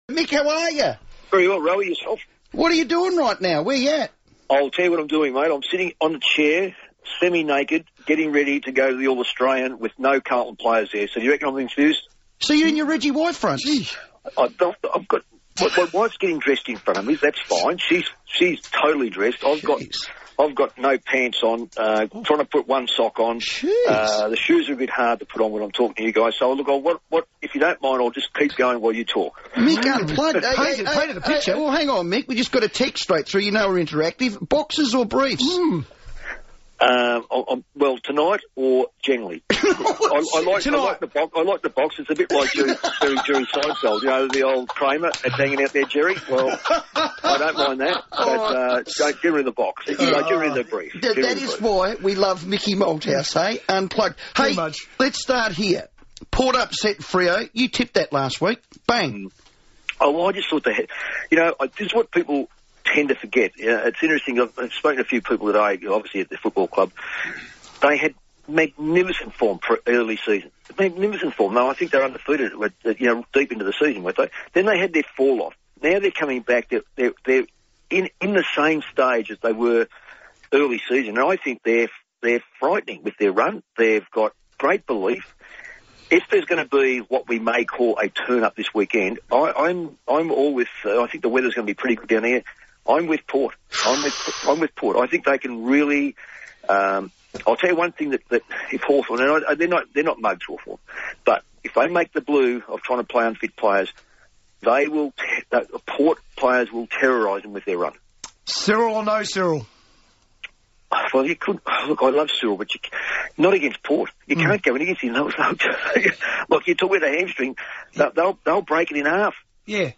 A relaxed Mick Malthouse recently chatted to Adelaide's FIVEaa about the 2014 AFL Finals Series.